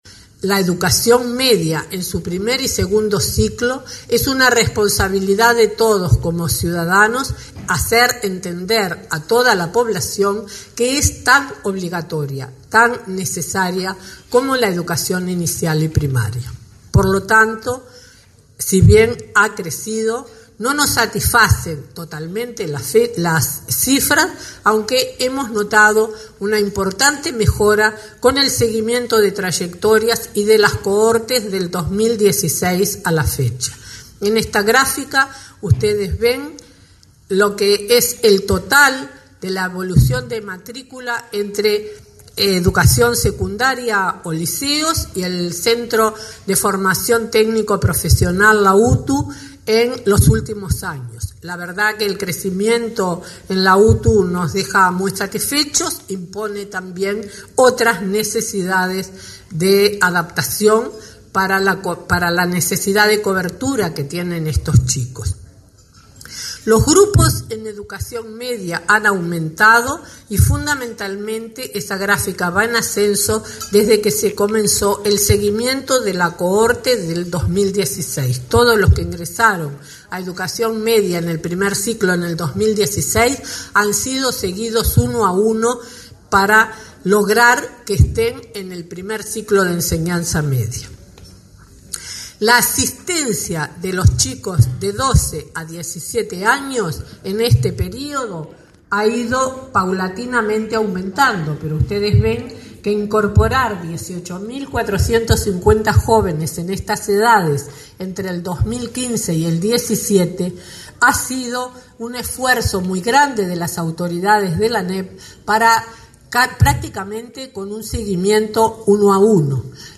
“Entre 2015 y 2017 se incorporaron 18.450 jóvenes a la educación media, gracias al seguimiento uno a uno que se realiza para garantizar su permanencia en el sistema”, destacó la ministra de Educación, María Julia Muñoz, en un desayuno de trabajo. Reconoció que aún resta mucho trabajo, pero valoró que la matrícula creciera en los quintiles de población vulnerable, por los nuevos formatos pedagógicos y diversos programas.